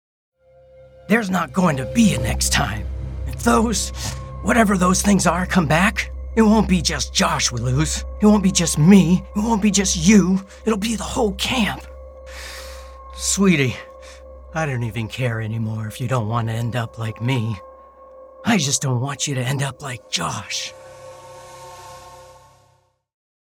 Male
Character / Cartoon
Video Game Sample